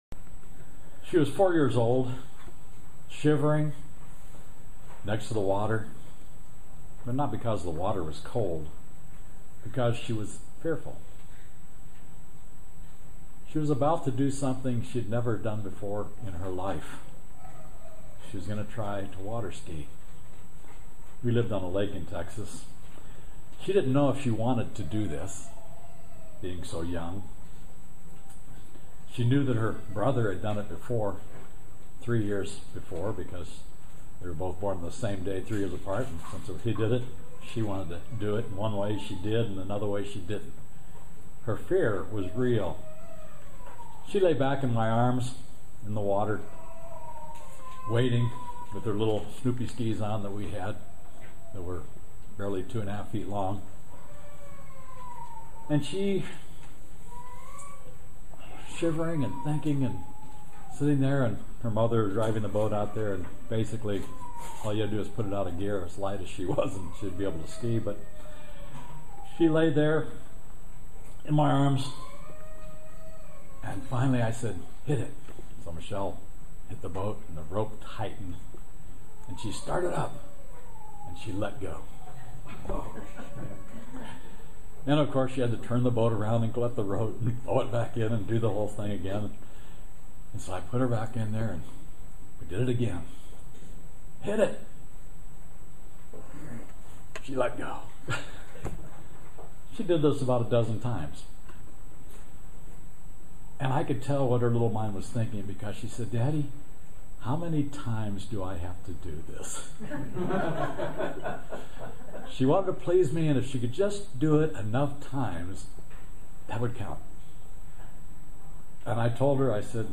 Join us for this eye opening video sermon about not quitting or giving up. Has God prepared us for things we're afraid of? Does God have a future He is preparing us for now?